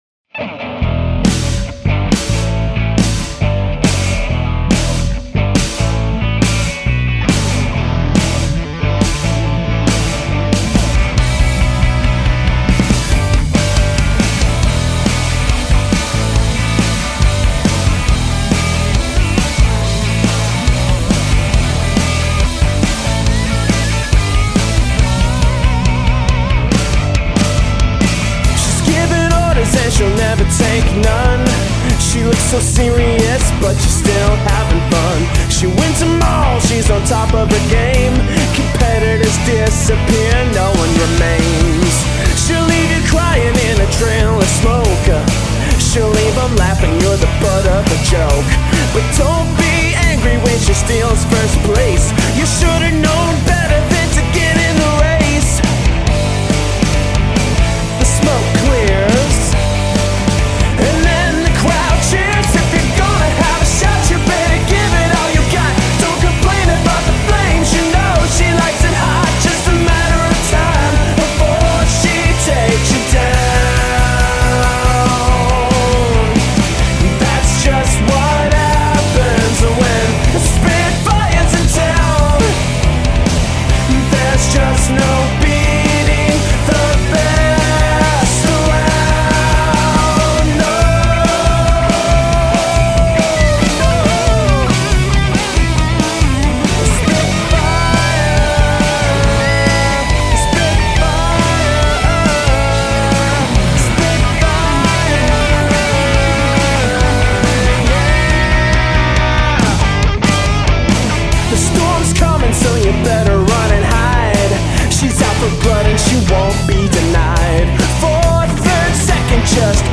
classic rock song
guitar, so he decided to shred a little bit on this track